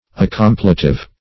Search Result for " accompletive" : The Collaborative International Dictionary of English v.0.48: Accompletive \Ac*com"ple*tive\, a. [L. ad + complere, completum, to fill up.]